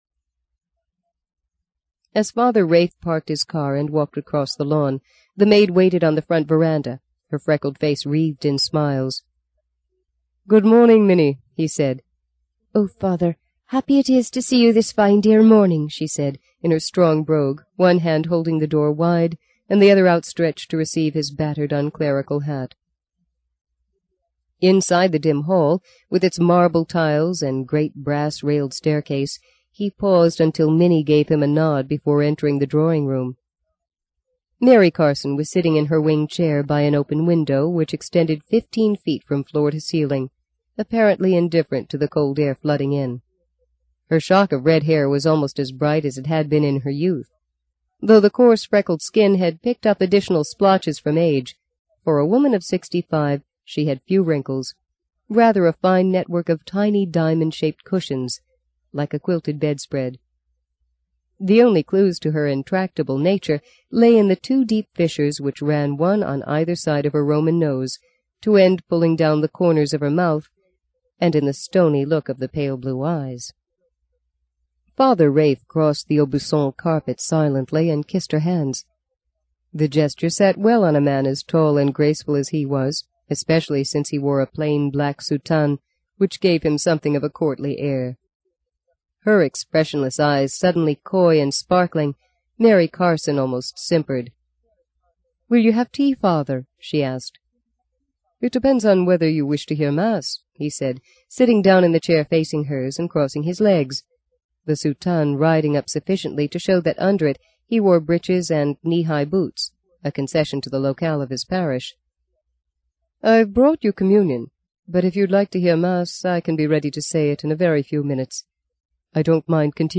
在线英语听力室【荆棘鸟】第三章 03的听力文件下载,荆棘鸟—双语有声读物—听力教程—英语听力—在线英语听力室